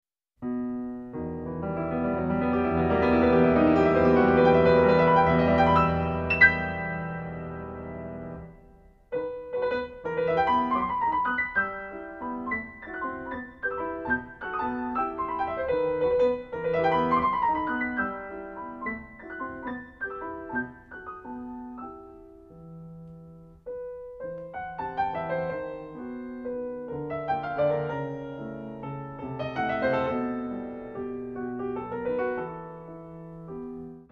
Pianist
refined, patrician touch
E minor, Op. Posth.